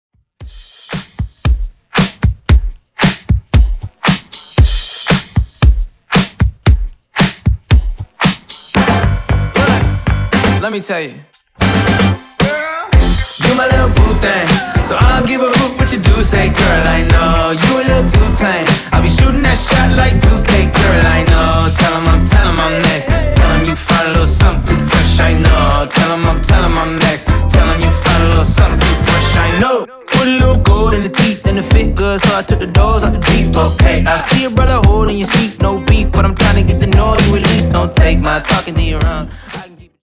Genre: MASHUPS
Clean BPM: 162 Time